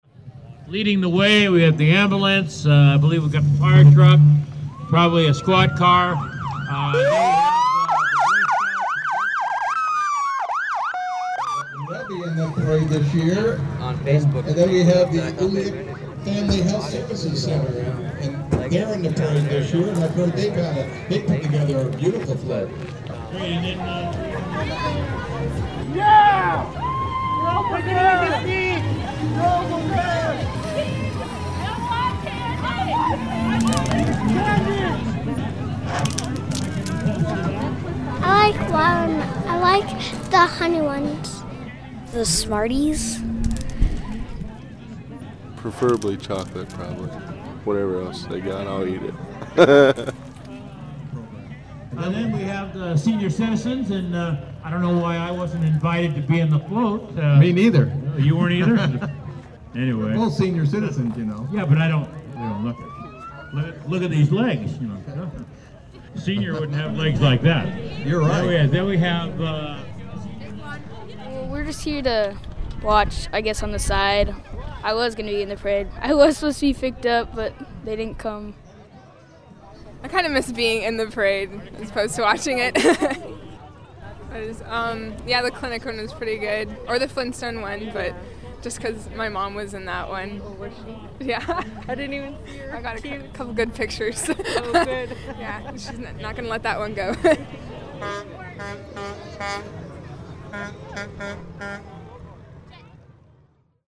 05_fourth_of_july_pkg.mp3